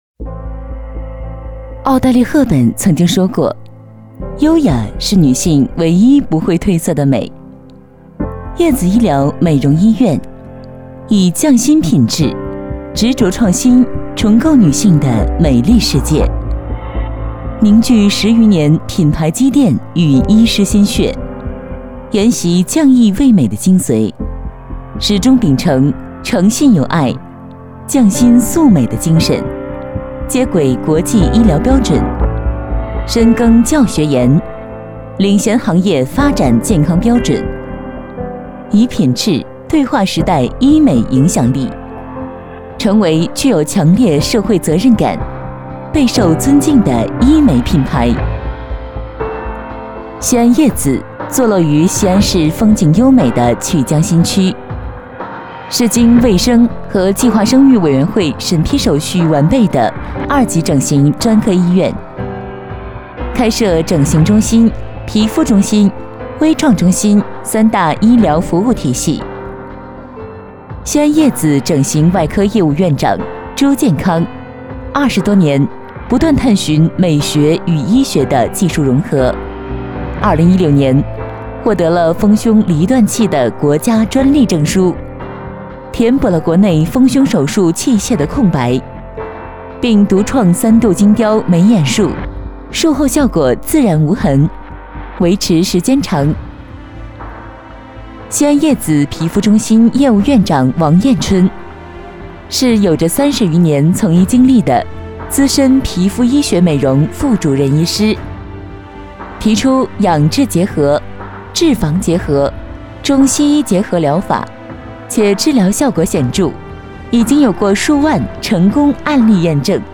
女国127_专题_医院_叶子医美整形机构_稳重.mp3